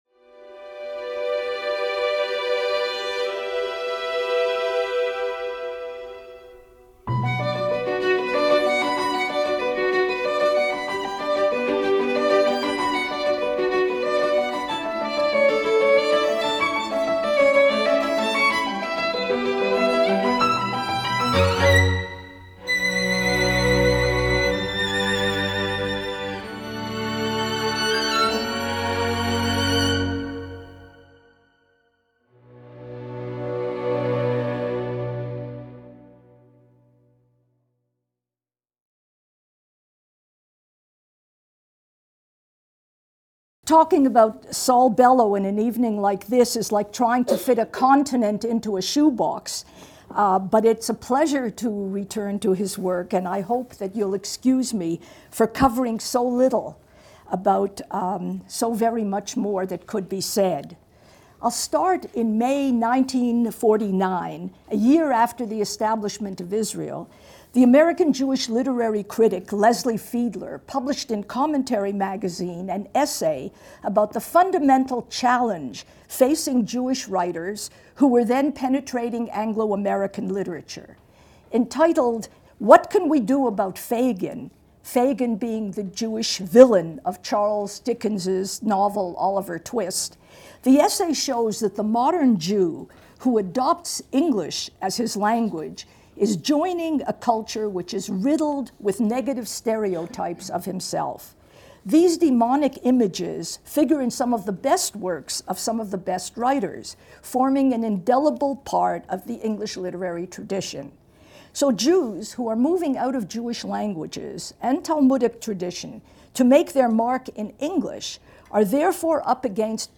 In our final lecture, Professor Wisse takes up one of the most decorated novelists in American history: Saul Bellow.